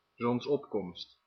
Ääntäminen
IPA : /ˈsʌn.ɹaɪz/